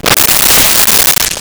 Whip Web Splat
Whip Web Splat.wav